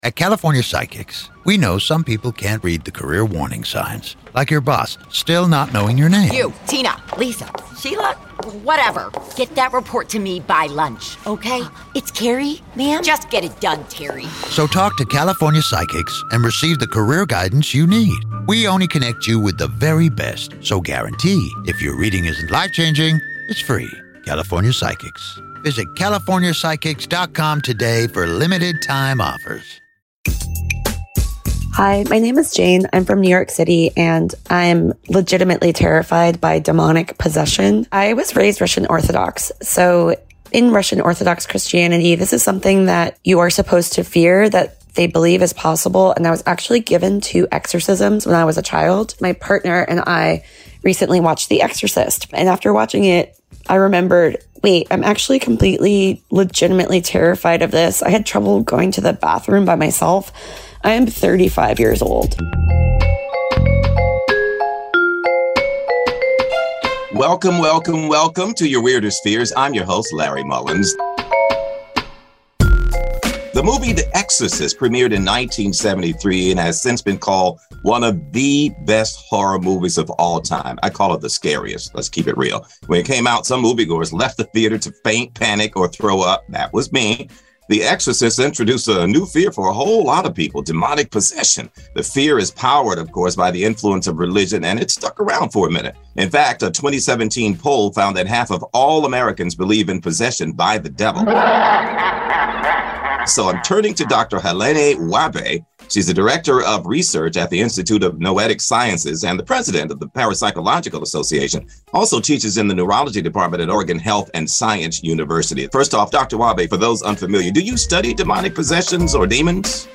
Listen as the two break down the stigmas surrounding demonic possession and learn what you can do if you ever find yourself in the presence of spirit.